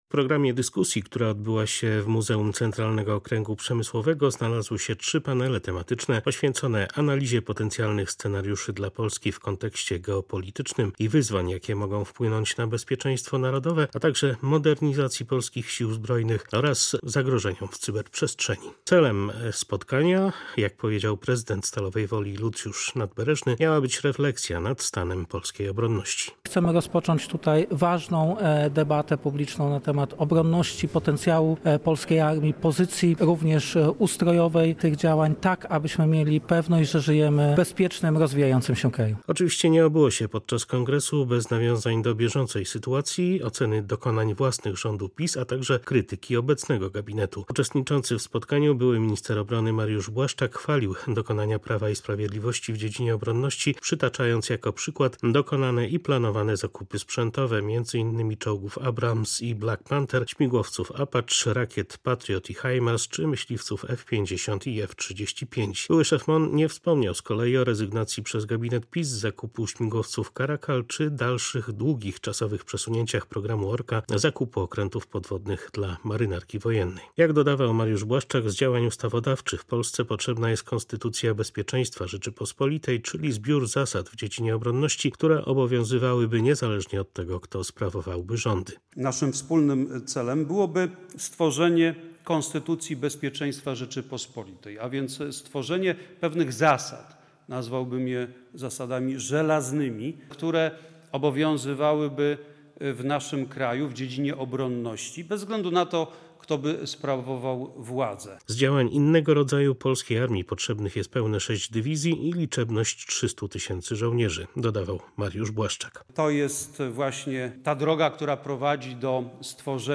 – Stworzenie Konstytucji Bezpieczeństwa Rzeczypospolitej, czyli żelaznych zasad obronności, które obowiązywałyby w Polsce bez względu, kto sprawuje władzę, jest naszym celem – powiedział Mariusz Błaszczak w sobotę podczas I Kongresu Armia w Stalowej Woli.